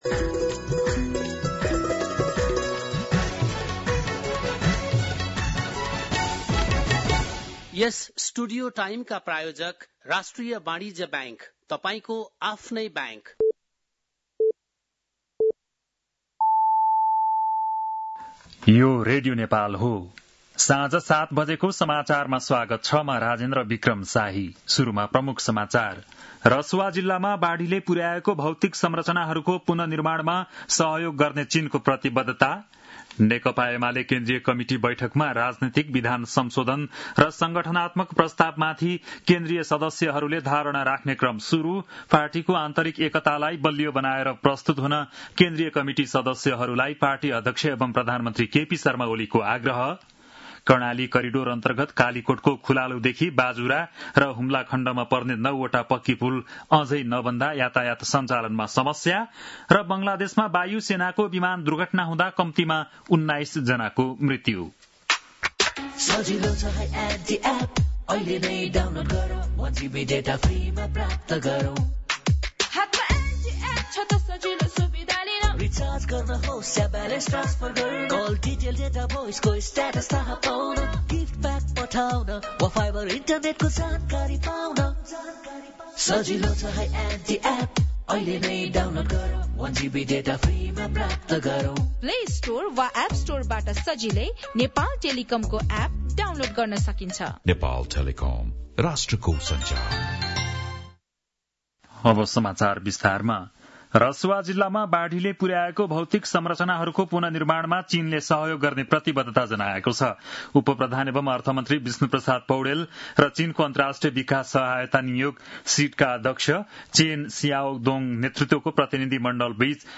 An online outlet of Nepal's national radio broadcaster
बेलुकी ७ बजेको नेपाली समाचार : ५ साउन , २०८२
7.-pm-nepali-news-4-05.mp3